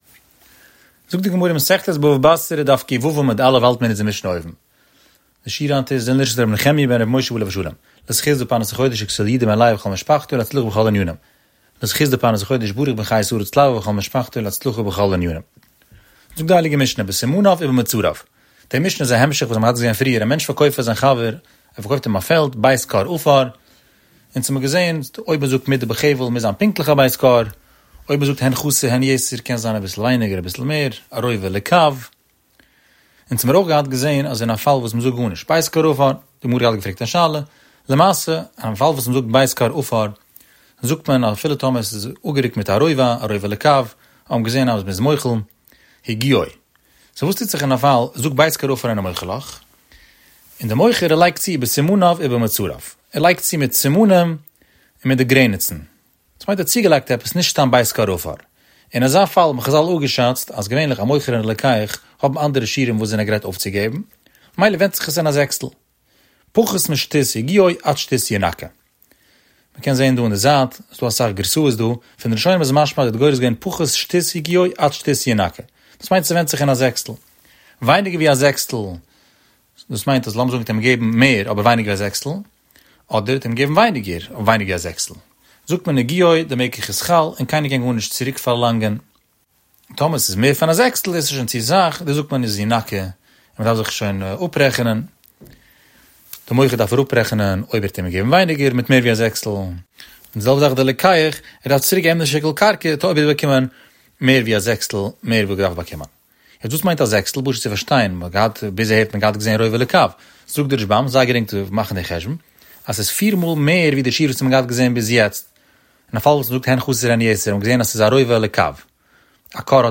23 Minute Daf